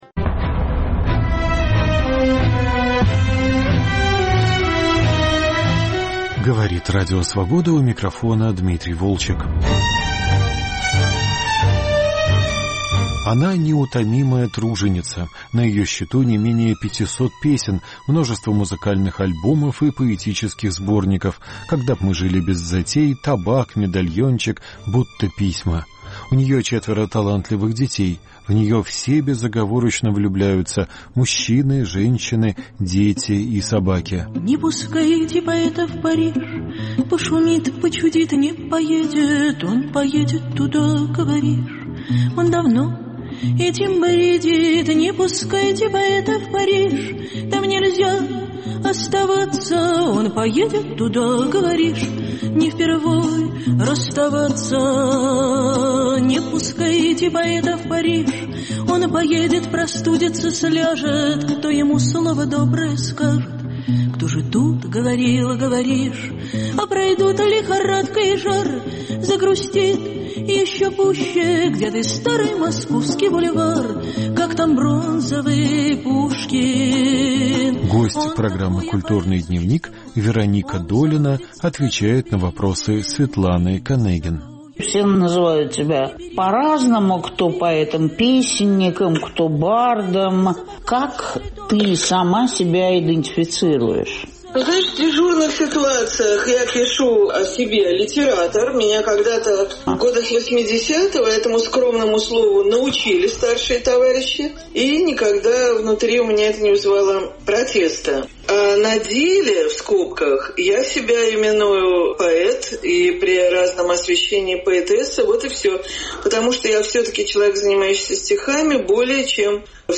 Разговор с Вероникой Долиной